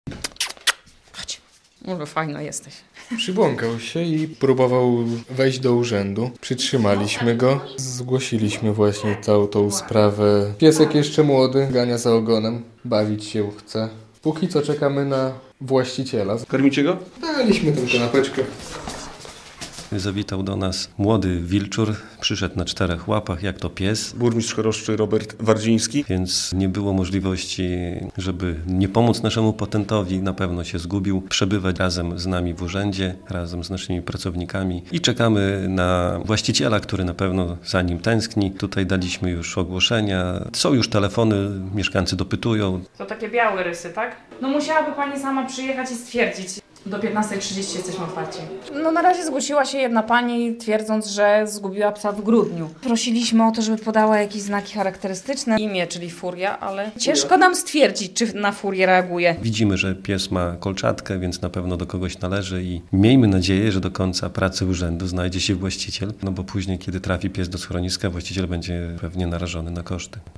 Nietypowy petent w Urzędzie Miejskim w Choroszczy - relacja
- Chcemy pomóc naszemu wyjątkowemu petentowi, bo ewidentnie się zgubił - mówił burmistrz Choroszczy Robert Wardziński.